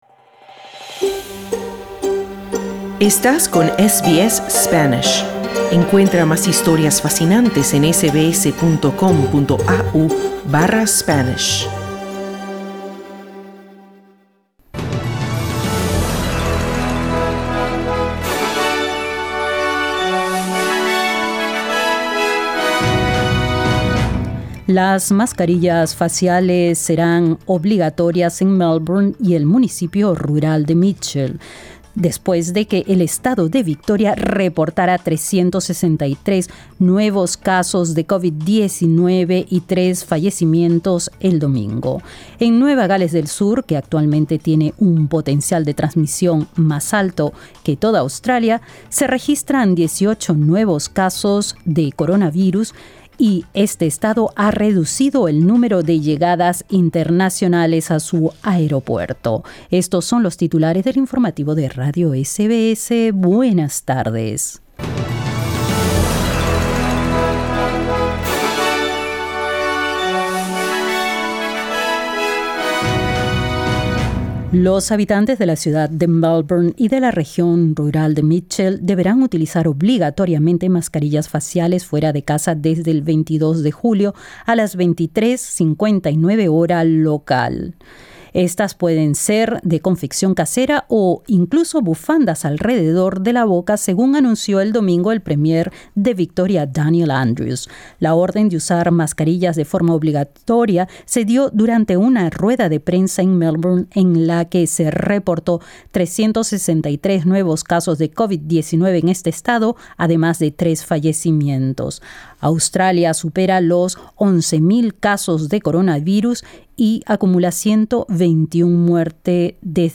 Los habitantes de Victoria y el municipio de Mitchell deberán utilizar obligatoriamente mascarillas desde el 22 de julio a las 11.59 hora local. Escucha esta y otras noticias en nuestro boletín informativo.